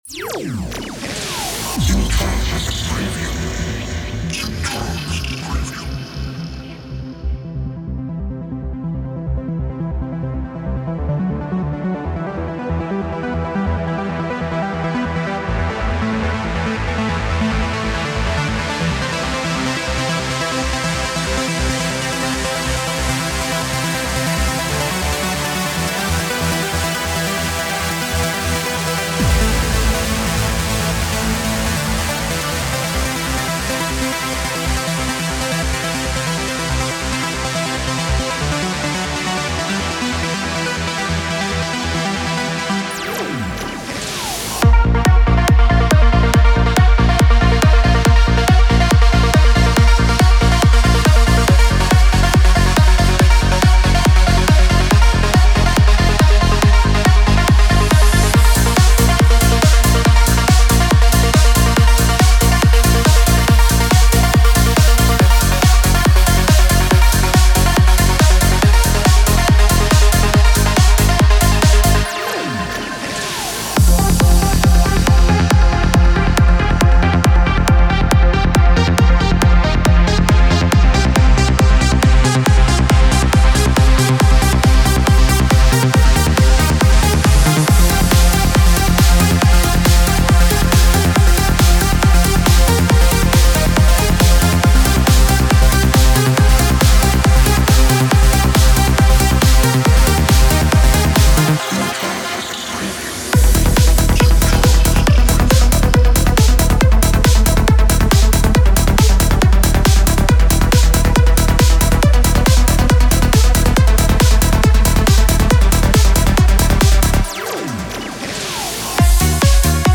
Psy-Trance Trance Uplifting Trance